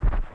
WAV · 16 KB · 單聲道 (1ch)